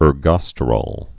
(ûr-gŏstə-rôl, -rōl)